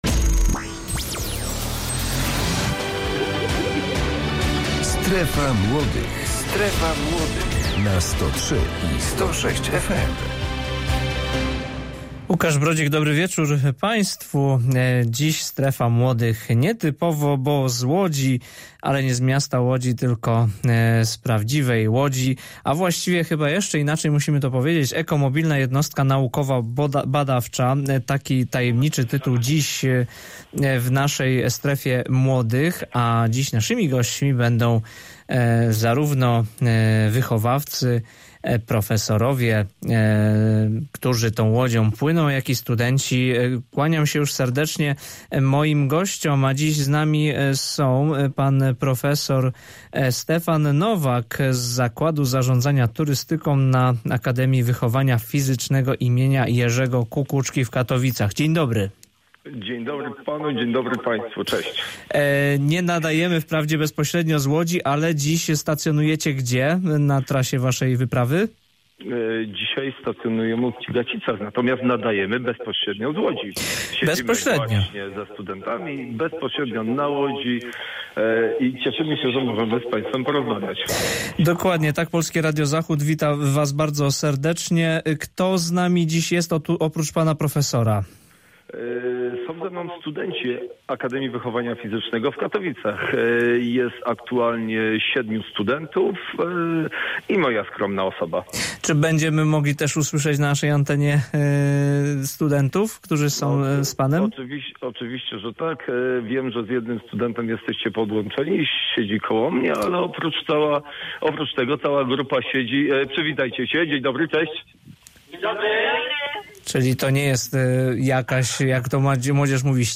Dzisiejsza Strefa Młodych pierwszy raz nadawana jest zdalnie prosto z jachtu zacumowanego na rzece w Cigacicach.